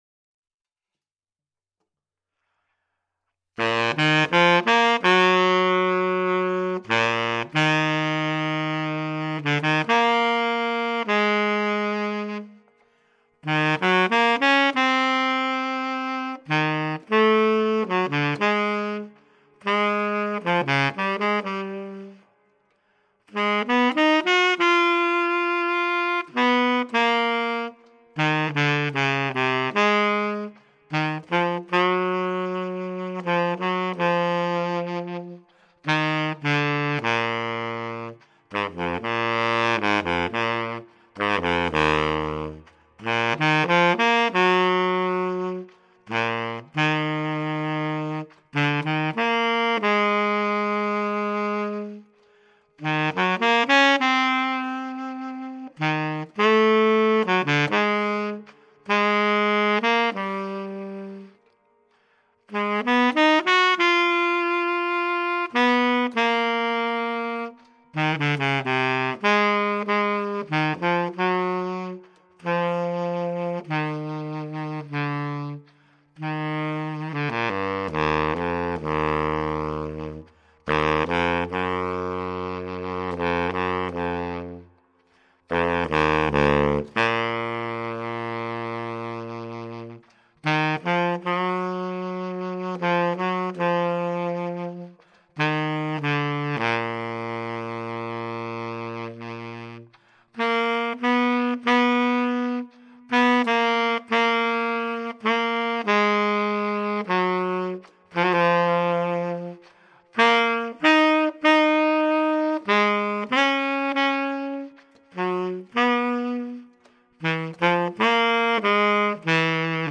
Quelques morceaux enregistrés en Solo au Saxophone Baryton